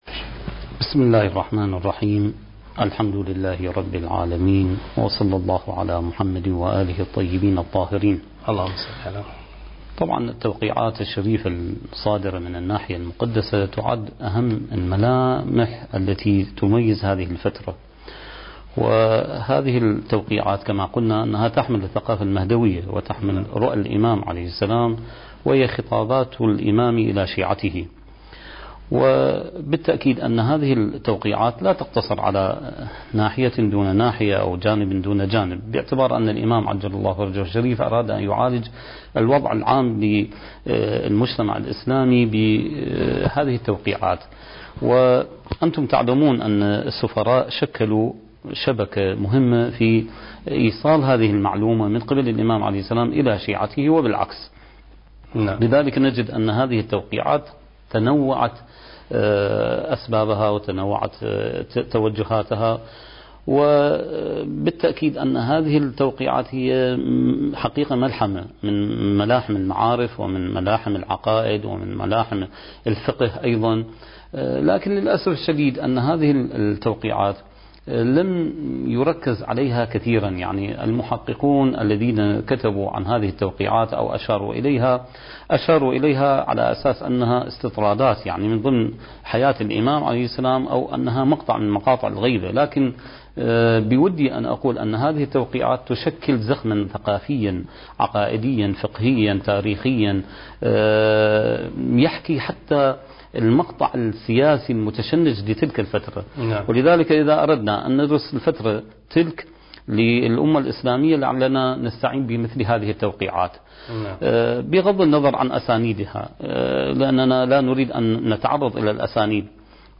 سلسلة محاضرات: بداية الغيبة الصغرى (5) برنامج المهدي وعد الله انتاج: قناة كربلاء الفضائية